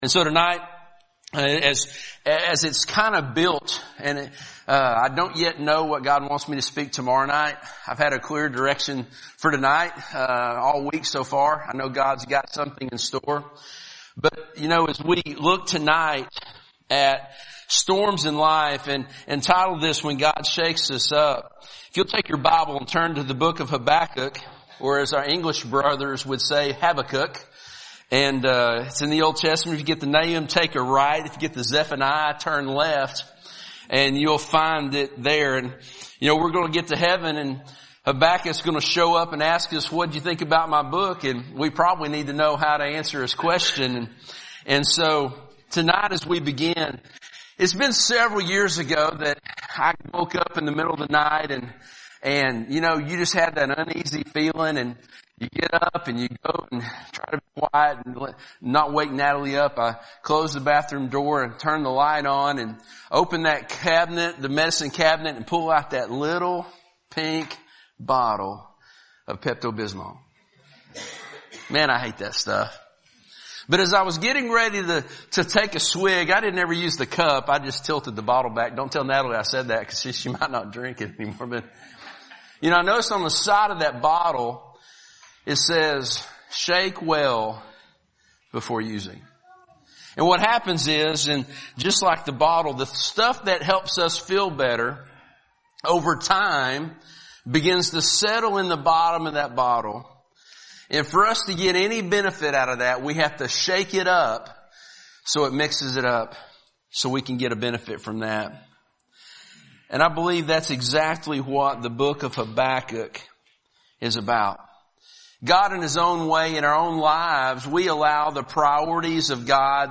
Fall Revival 2019 – Sermon 3